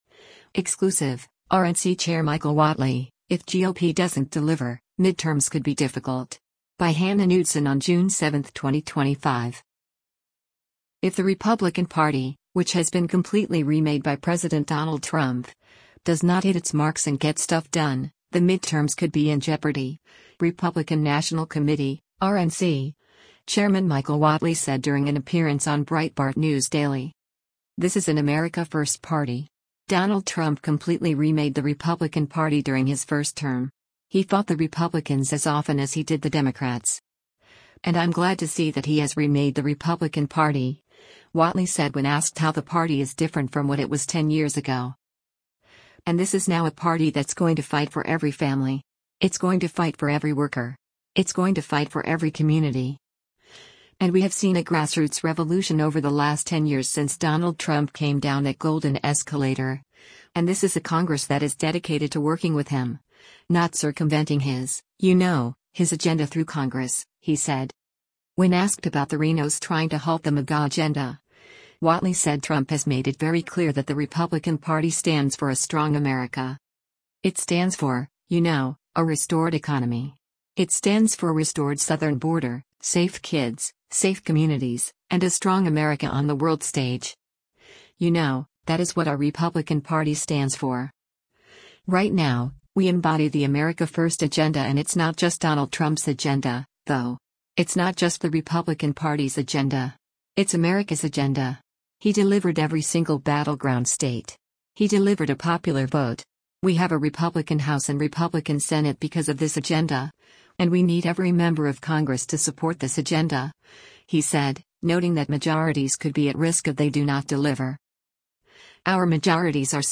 If the Republican Party — which has been “completely remade” by President Donald Trump — does not hit its marks and get stuff done, the midterms could be in jeopardy, Republican National Committee (RNC) Chairman Michael Whatley said during an appearance on Breitbart News Daily.
Breitbart News Daily airs on SiriusXM Patriot 125 from 6:00 a.m. to 9:00 a.m. Eastern.